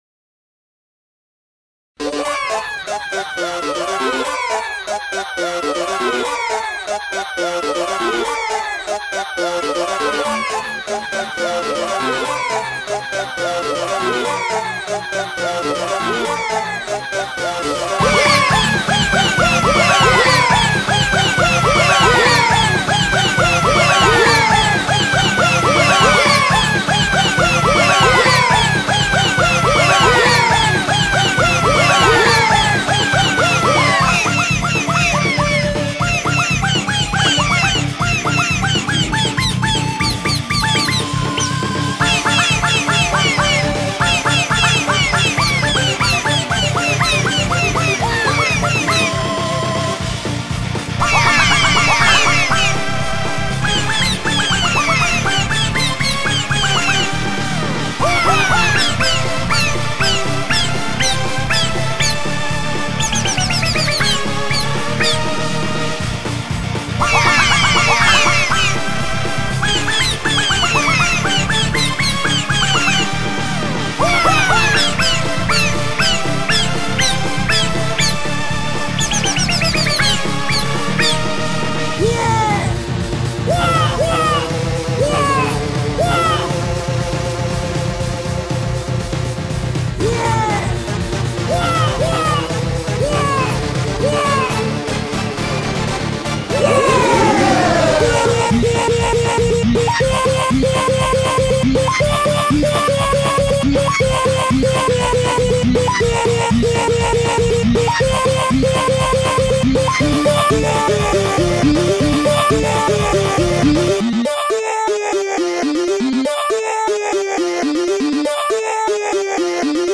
BPM120-240